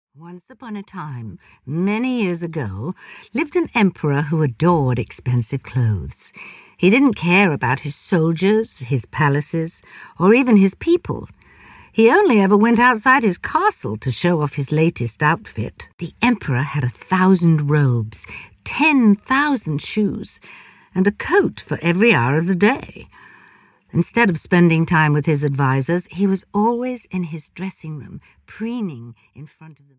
The Emperor’s New Clothes (EN) audiokniha
Ukázka z knihy
• InterpretDame Joan Collins